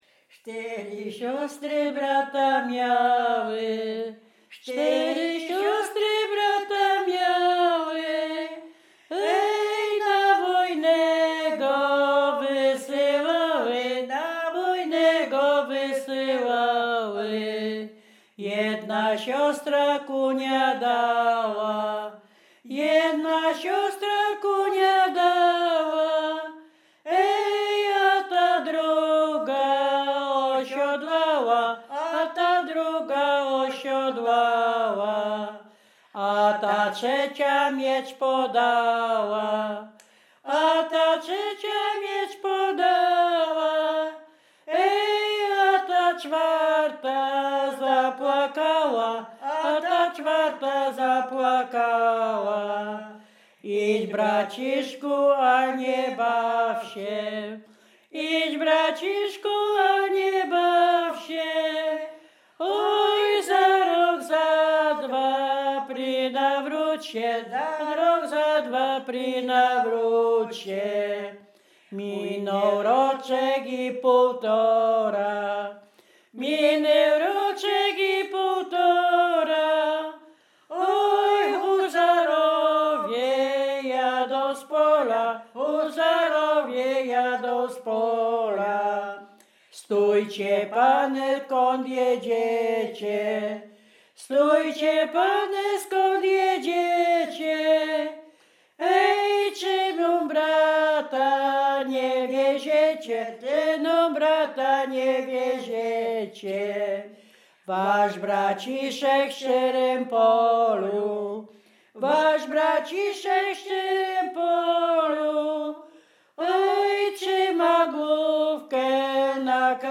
województwo dolnośląski, powiat lwówecki, gmina Lwówek Śląski, wieś Zbylutów
Ballada
ballady liryczne wojenkowe rekruckie